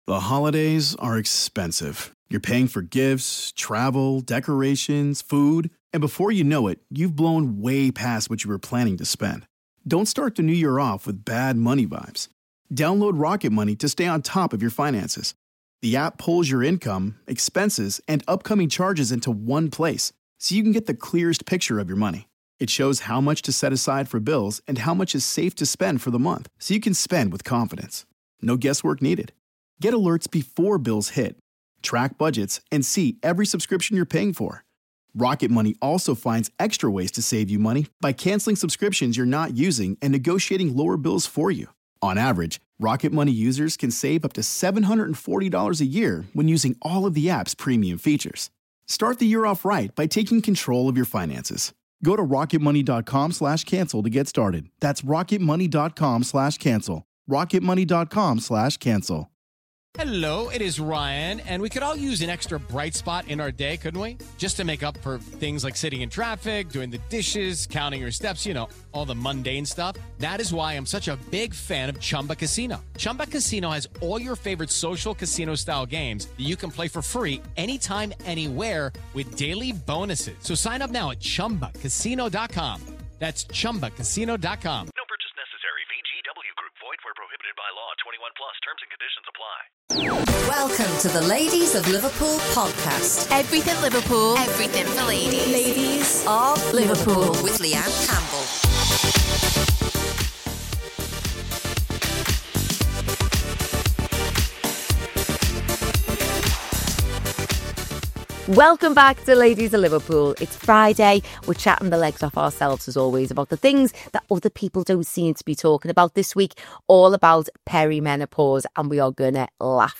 It’s an honest, uplifting conversation about reinvention, resilience, and reclaiming your power at every stage of life.